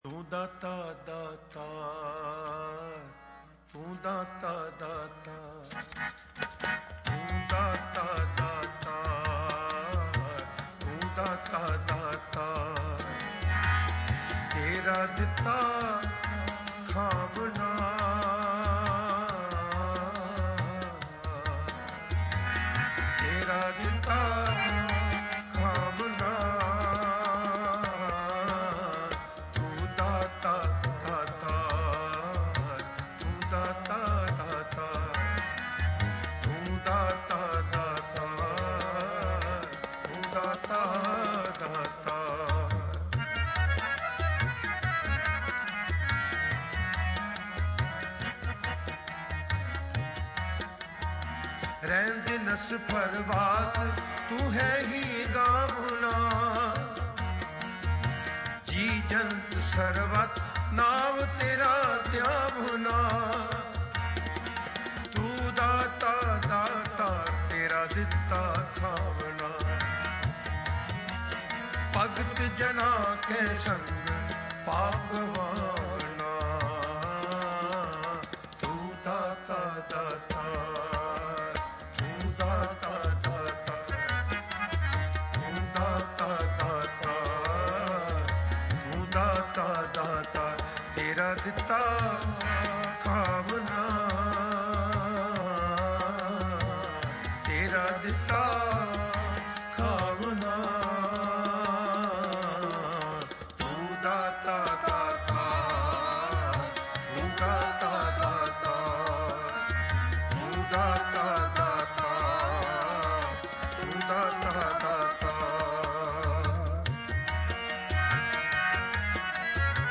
Mp3 Files / Gurbani Kirtan / Fremont Recorded /